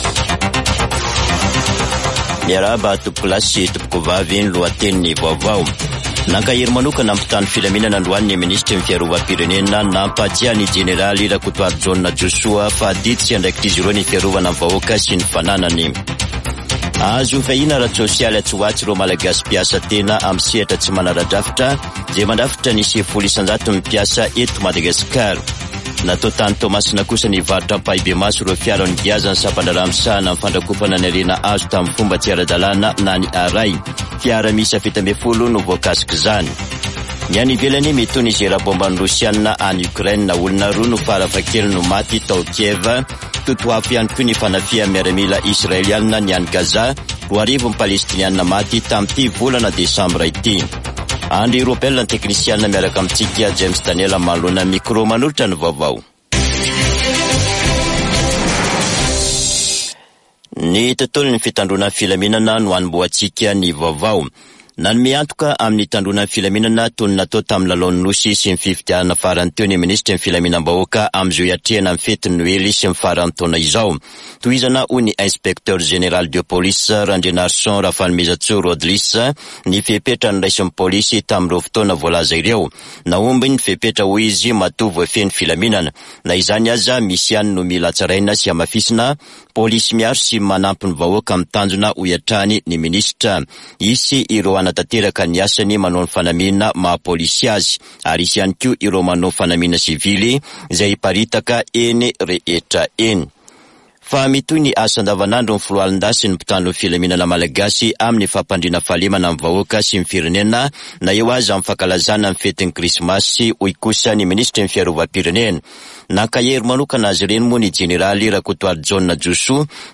[Vaovao hariva] Zoma 22 desambra 2023